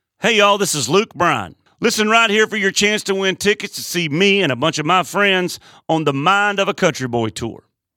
LINER Luke Bryan (listen right here to win tickets)